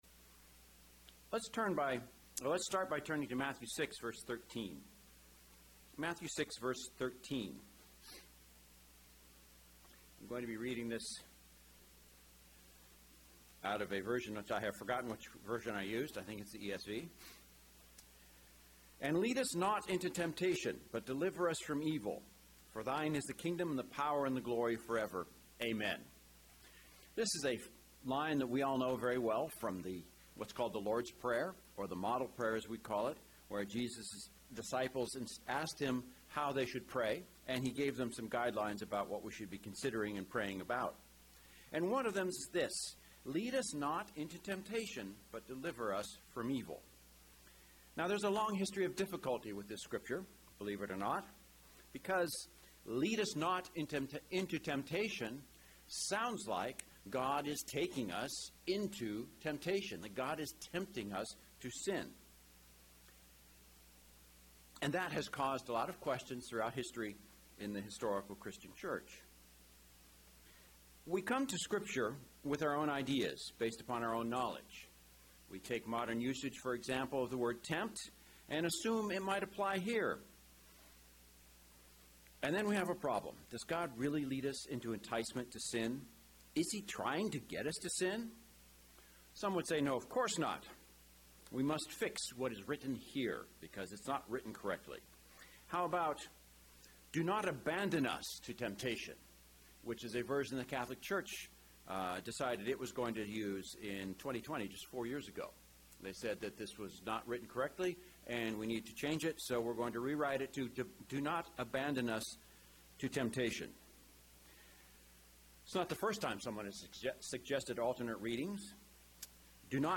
Given in Northern Virginia